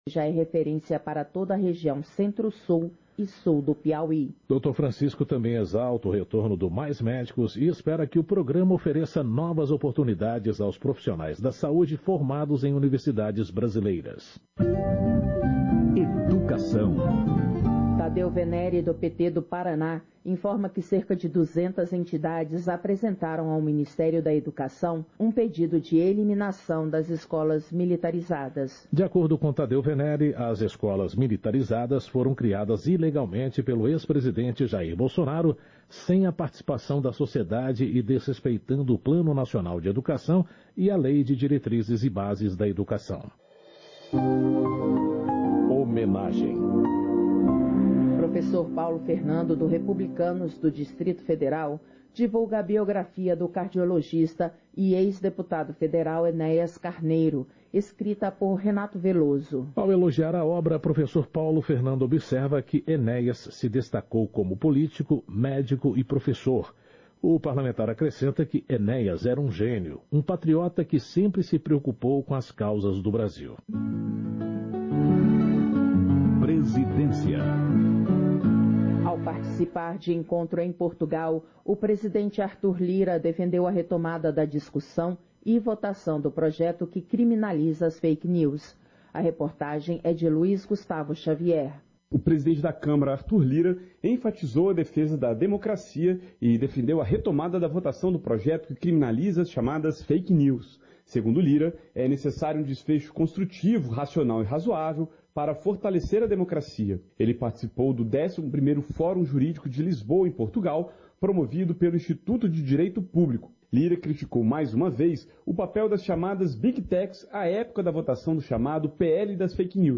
Sessão Ordinária 19/2023